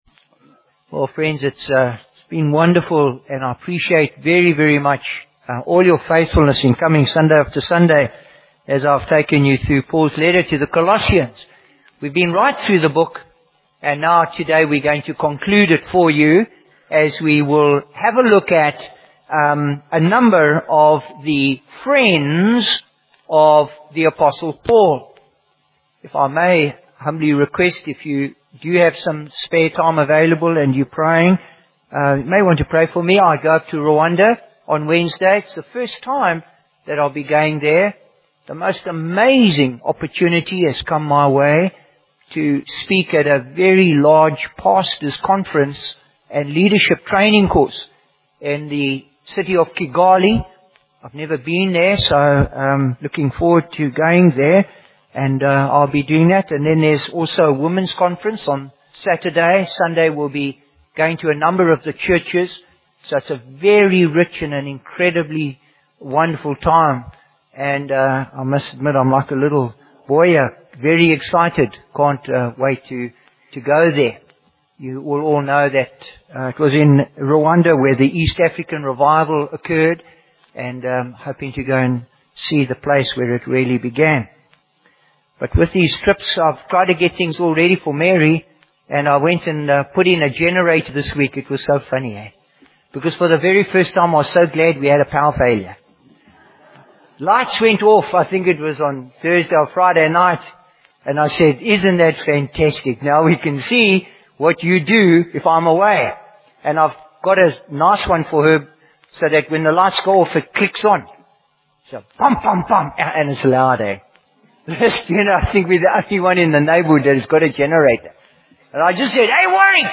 Bible Text: Colossians 4:7-14 | Preacher: Bishop Warwick Cole-Edwards | Series: Colossians